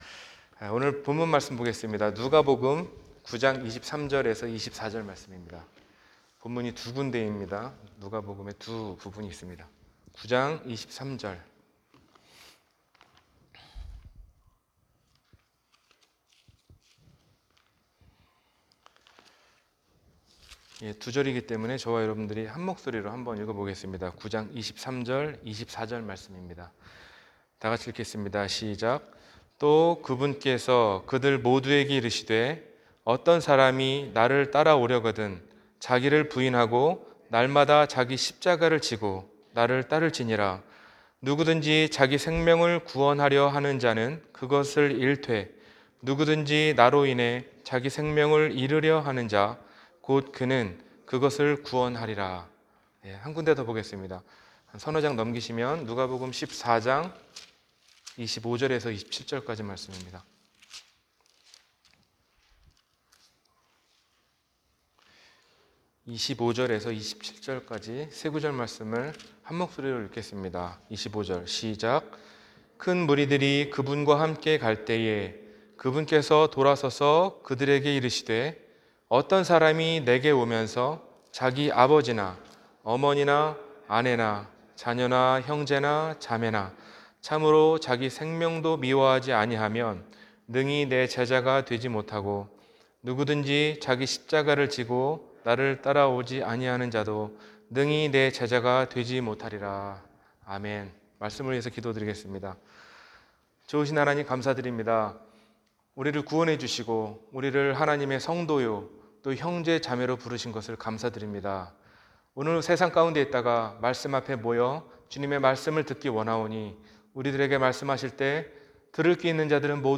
제자입니까? – 주일설교